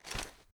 gear_rattle_weap_medium_05.ogg